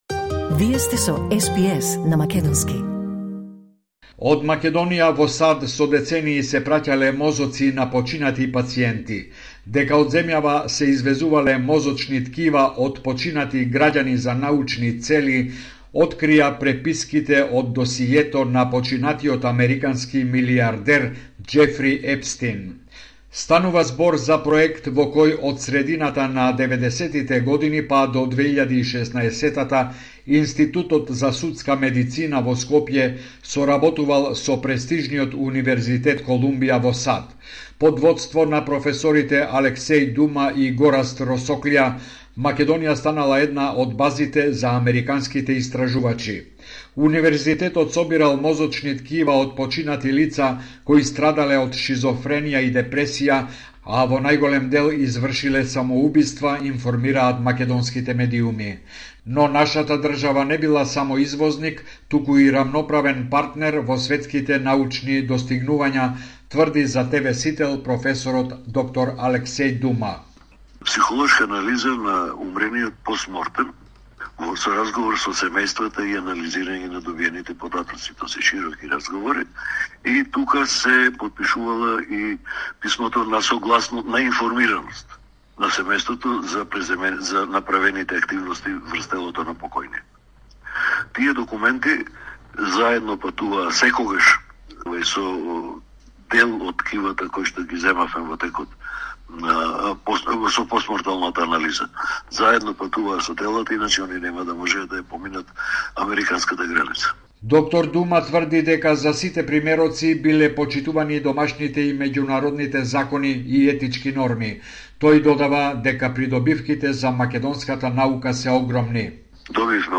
Извештај од Македонија 3 февуари 2026